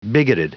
Prononciation du mot bigoted en anglais (fichier audio)
Prononciation du mot : bigoted